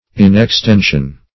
Inextension \In`ex*ten"sion\, n.